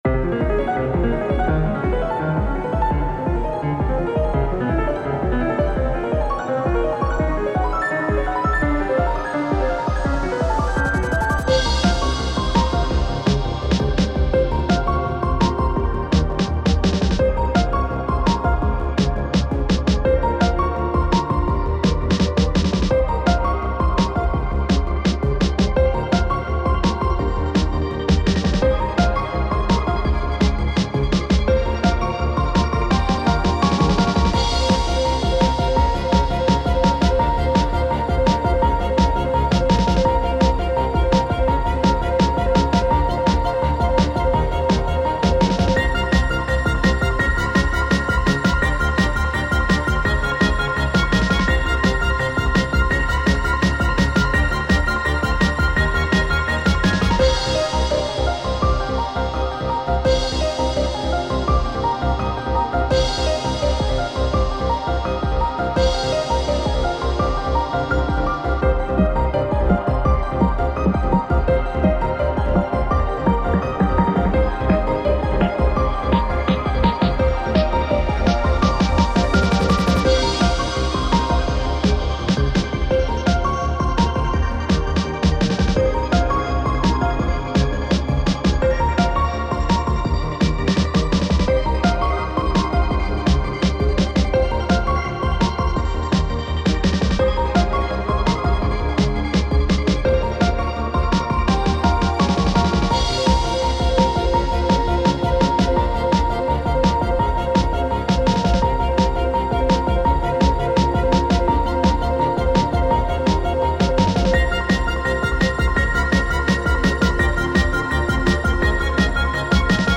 💬奇妙かつ幻想的な雰囲気のボスキャラをイメージした戦闘曲です。
どこか不安定な美しさを表現しています。